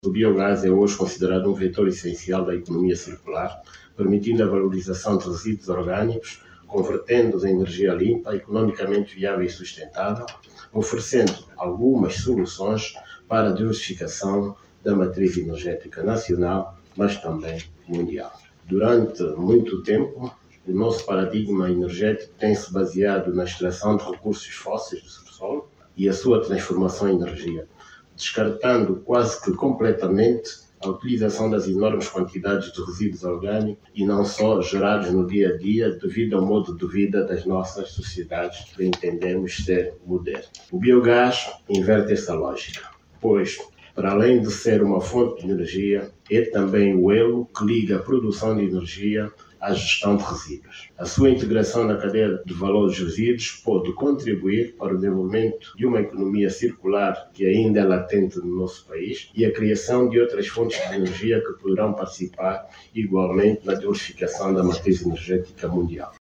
O Secretário de Estado dos Petróleos, José Barroso, alertou hoje, quinta-feira, 04, que Angola continua a desperdiçar uma quantidade significativa de resíduos com potencial para serem transformados em energia limpa. As declarações foram feitas durante o Workshop sobre Desenvolvimento do Biogás, que decorre em Luanda. José Barroso sublinhou que a aposta no biogás é estratégica, não apenas para a redução das emissões poluentes, mas também para a modernização da matriz energética nacional e para o impulso da economia.